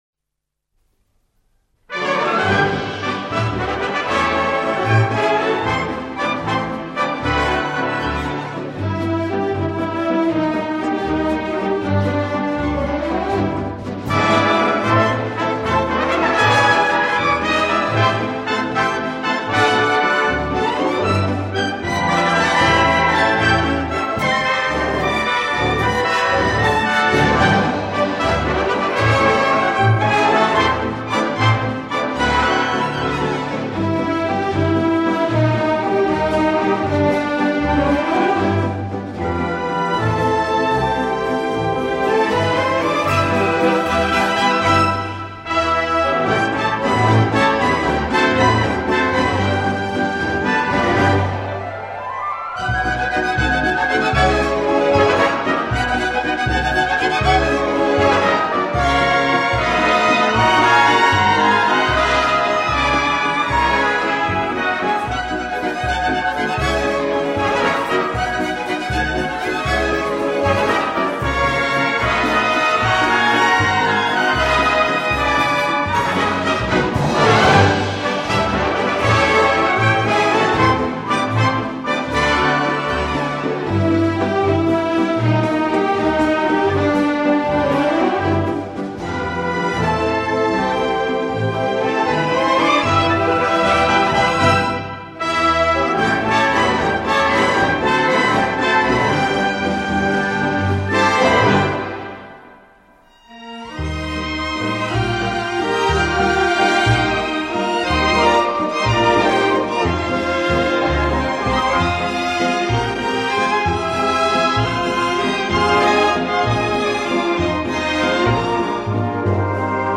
Две оркестровые пьесы в ритме вальса из Старого радио.